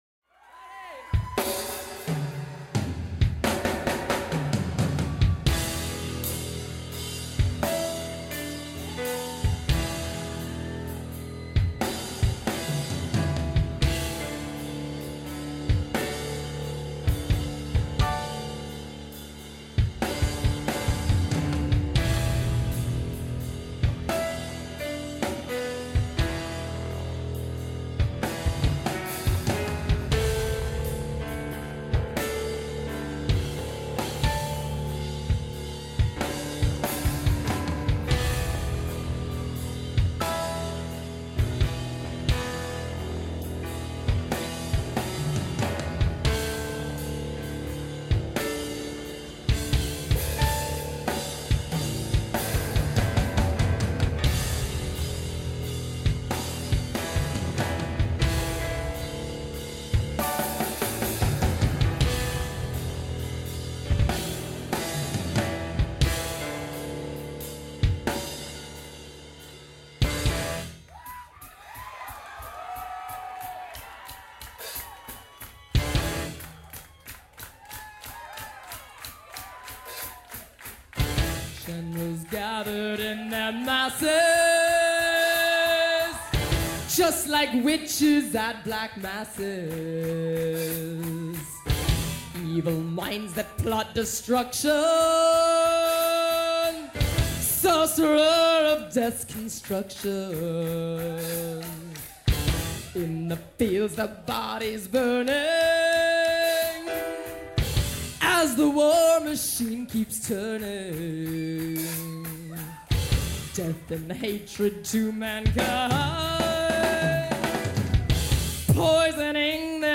” for their live shows